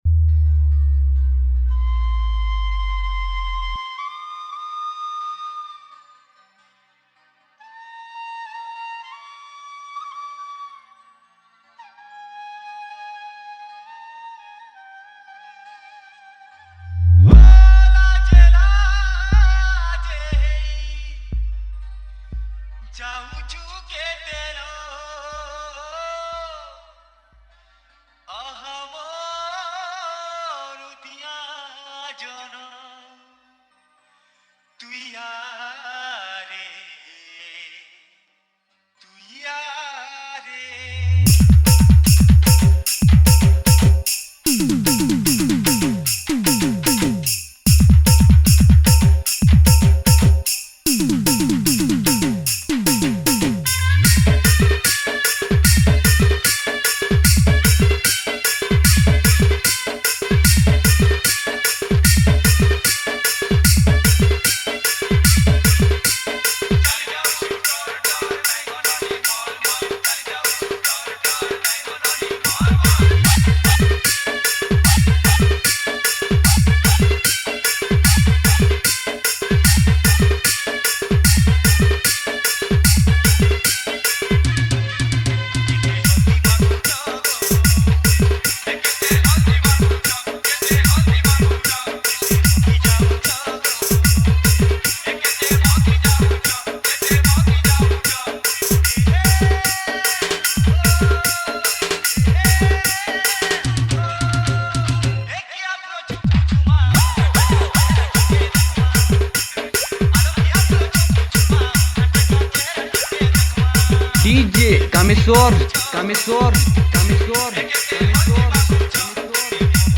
Category:  Old Sambalpuri Dj Song